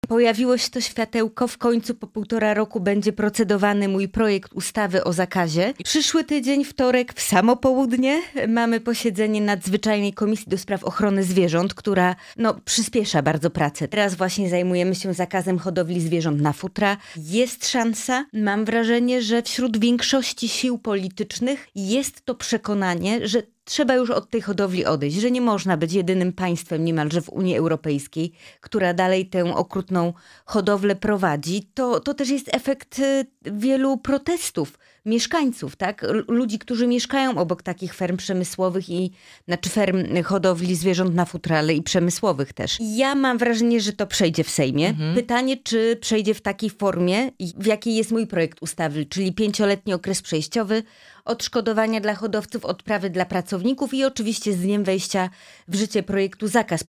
Posłanka na Sejm Małgorzata Tracz byłą naszym „Porannym Gościem”.